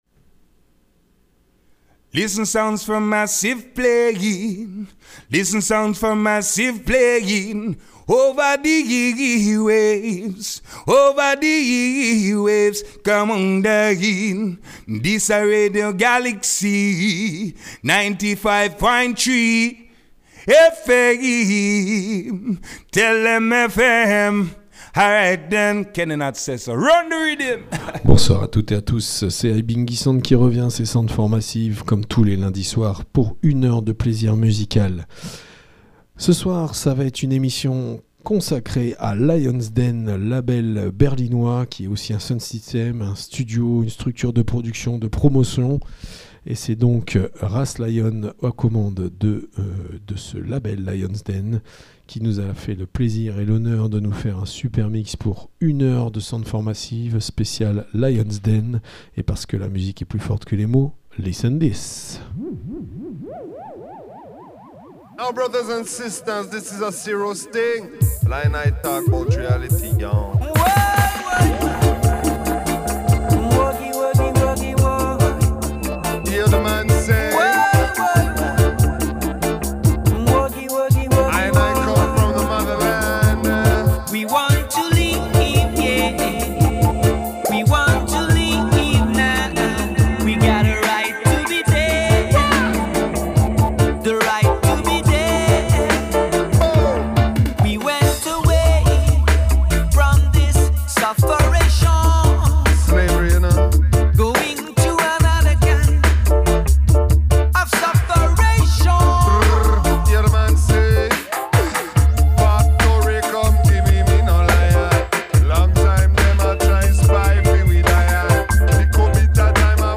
reggaephonique
enregistré lundi 24 novembre dans les studios